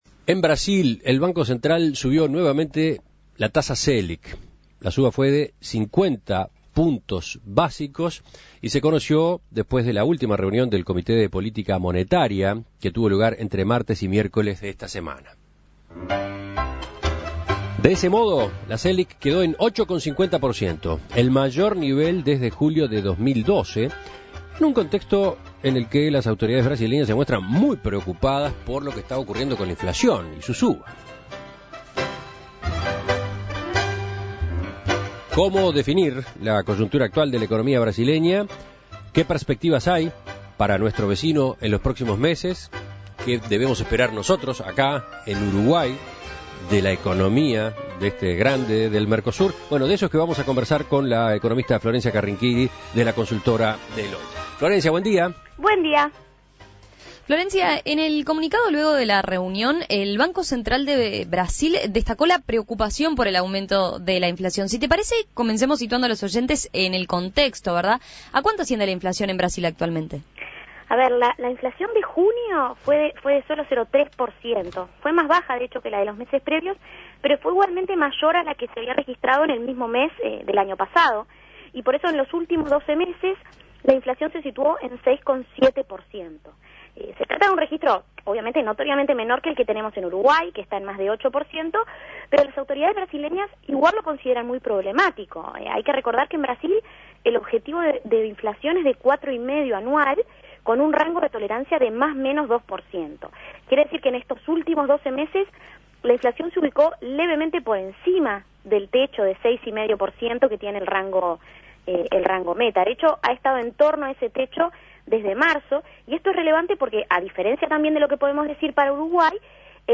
Análisis Económico El Banco Central de Brasil vuelve a subir la tasa Selic ante presiones inflacionarias que no ceden